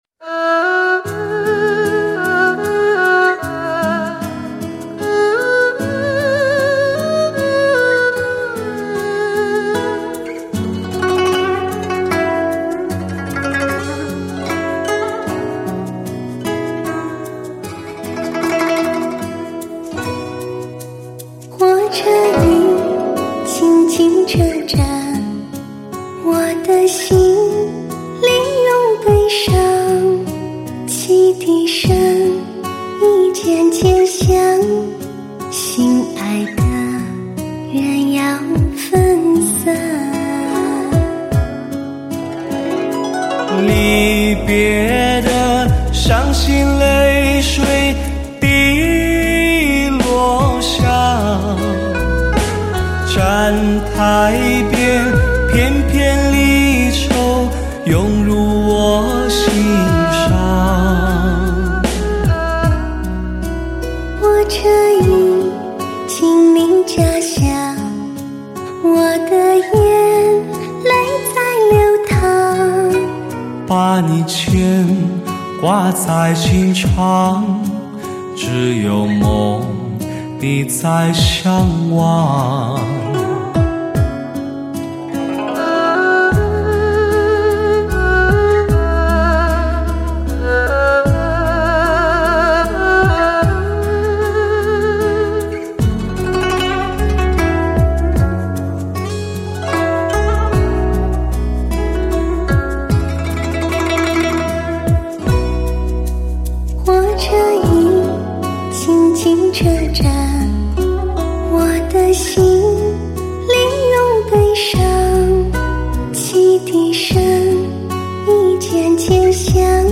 Surround7.1 全方位三维环绕
Hi-Fi人声高解析 高临场天碟